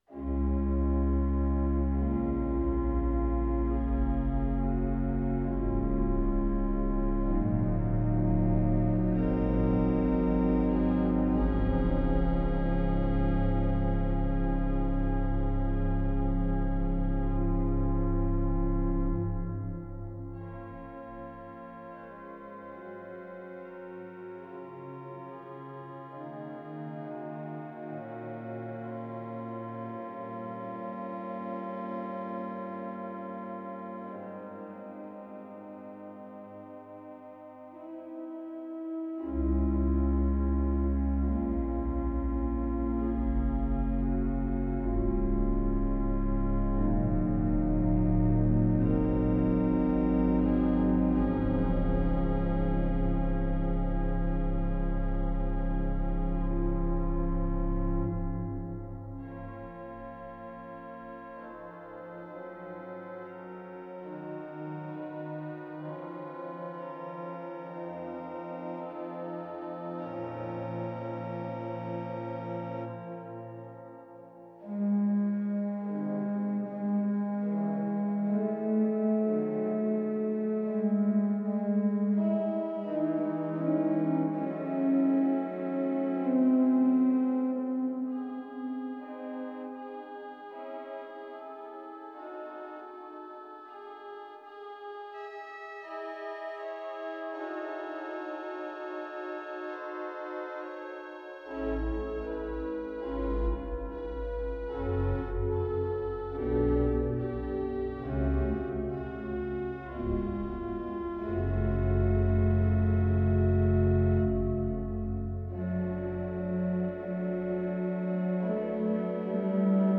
The arrangement we are working with here is the fourth in the series and has a strongly religious character.
For registration, we use only the soft 8’ stops on all manuals and couple them. The use of the swell box is also recommended.